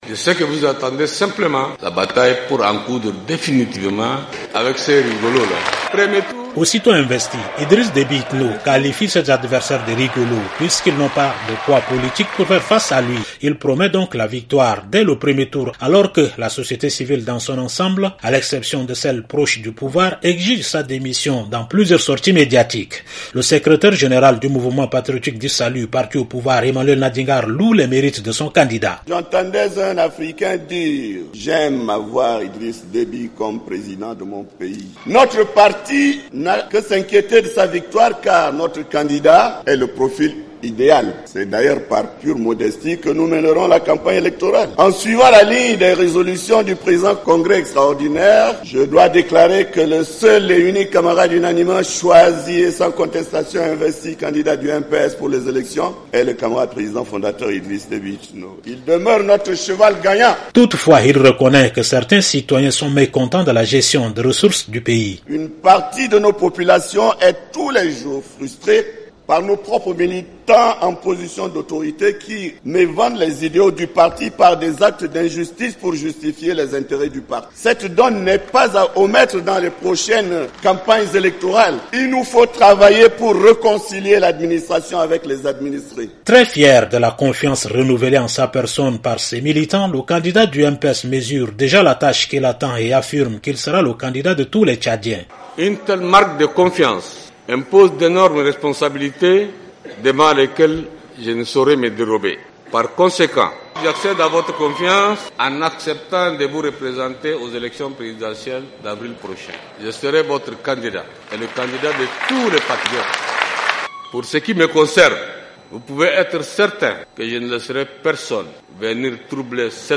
De N'Djaména, une correspondance